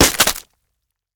Flesh Drop 2 Sound
horror